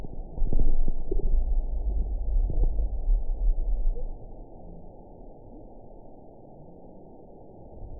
event 922096 date 12/26/24 time 10:42:51 GMT (11 months, 1 week ago) score 8.20 location TSS-AB10 detected by nrw target species NRW annotations +NRW Spectrogram: Frequency (kHz) vs. Time (s) audio not available .wav